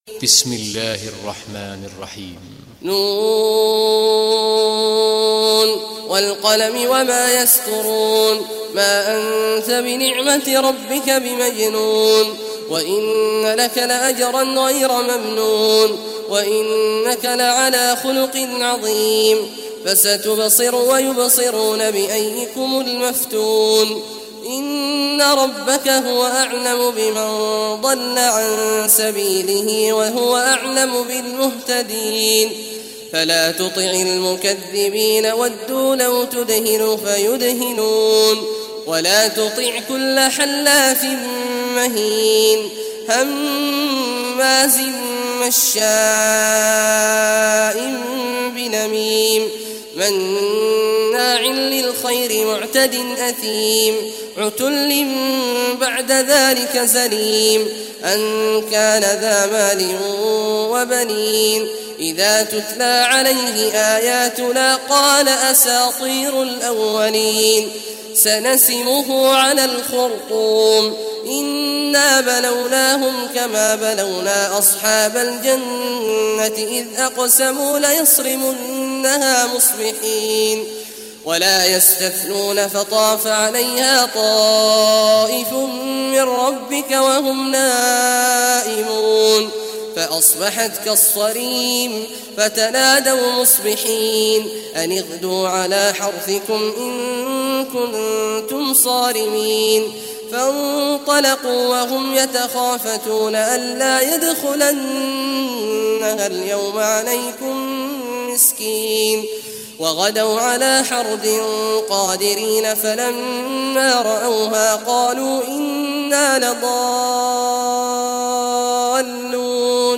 Surah Al-Qalam Recitation by Sheikh Awad Juhany
Surah Al-Qalam, listen or play online mp3 tilawat / recitation in Arabic in the beautiful voice of Sheikh Abdullah Awad al Juhany.